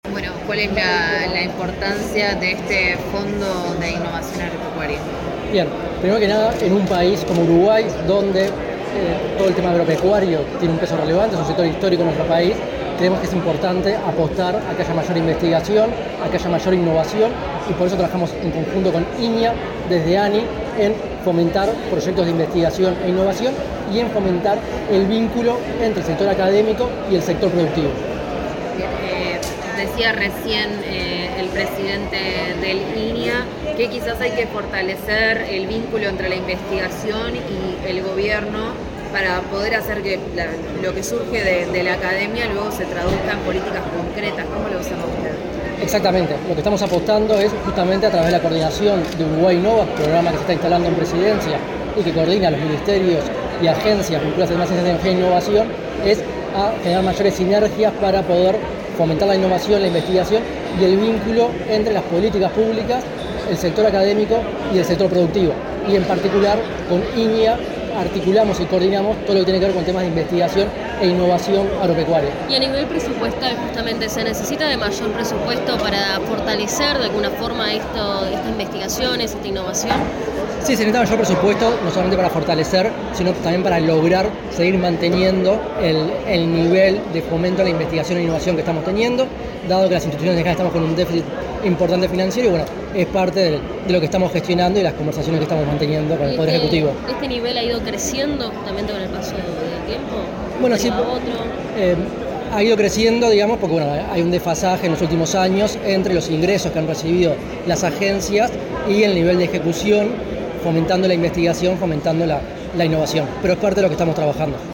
Declaraciones del presidente de la ANII, Álvaro Brunini
Declaraciones del presidente de la ANII, Álvaro Brunini 16/07/2025 Compartir Facebook X Copiar enlace WhatsApp LinkedIn En el marco de la presentación de proyectos por el Ministerio de Ganadería, Agricultura y Pesca, el Instituto Nacional de Investigación Agropecuaria y la Agencia Nacional de Investigación e Innovación (ANII), el presidente de la última entidad citada, Álvaro Brunini, realizó declaraciones a los medios de prensa.